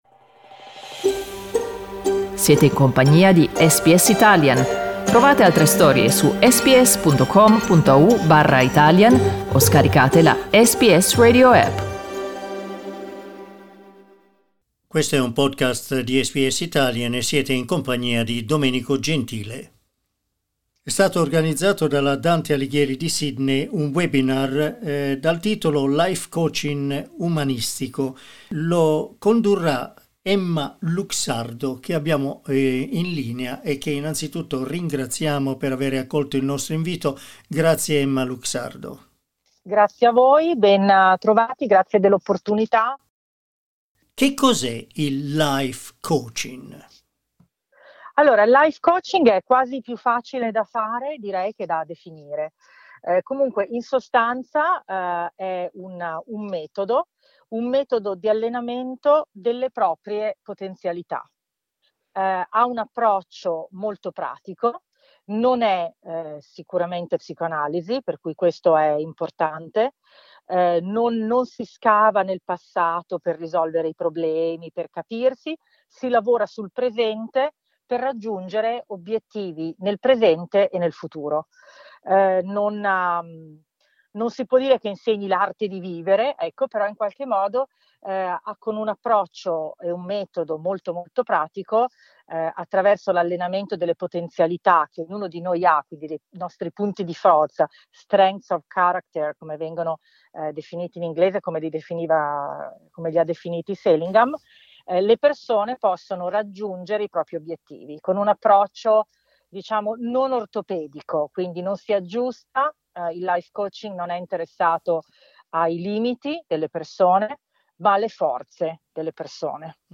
Ascolta l'intervista: LISTEN TO Il Life Coaching per raggiungere felicità e benessere SBS Italian 10:34 Italian Le persone in Australia devono stare ad almeno 1,5 metri di distanza dagli altri.